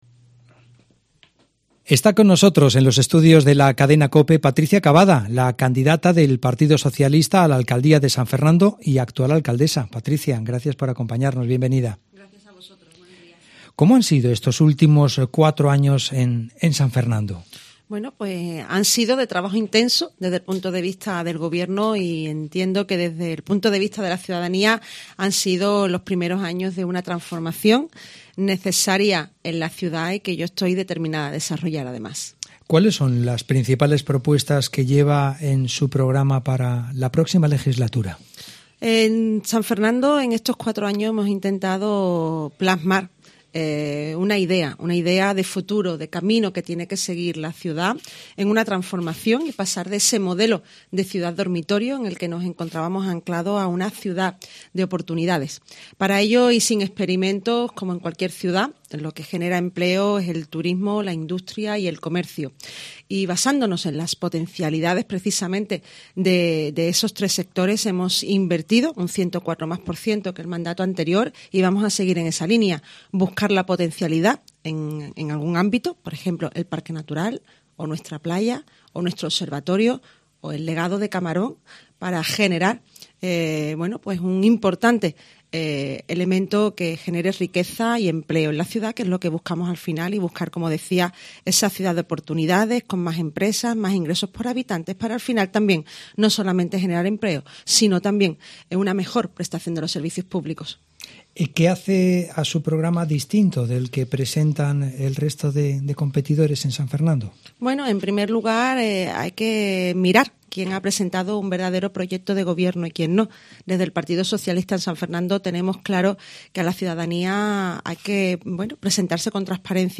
Entrevista Patricia Cavada, candidata del PSOE al Ayuntamiento de San Fernando